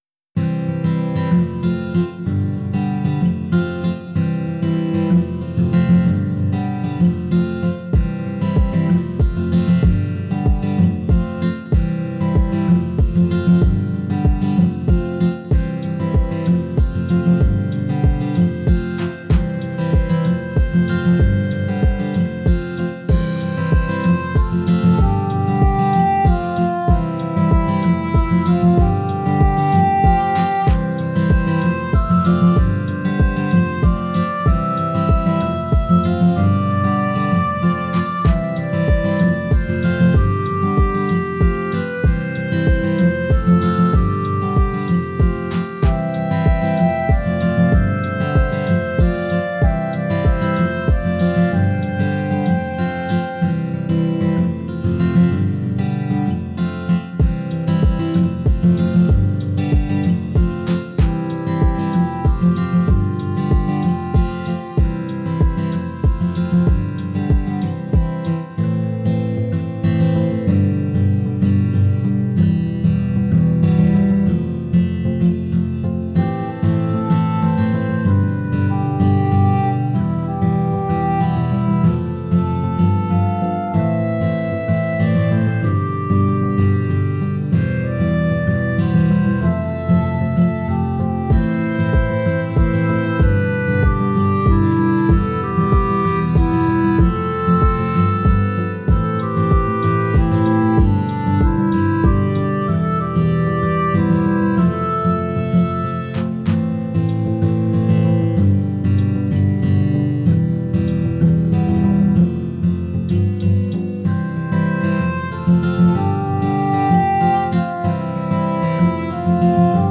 Tags: Clarinet, Woodwinds, Guitar, Percussion